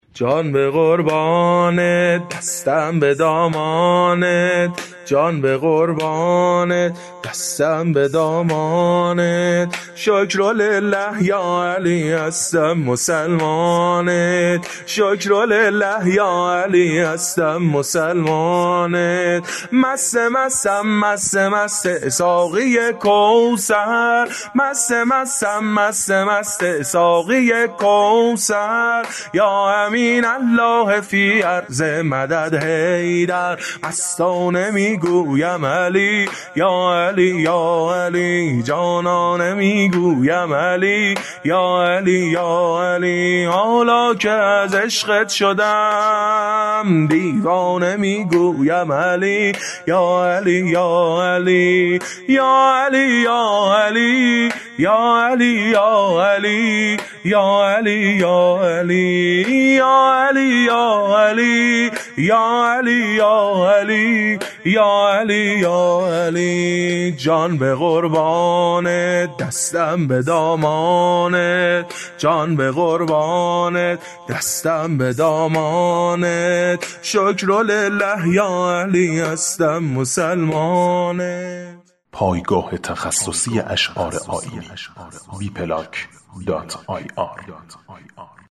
عید غدیر
سرود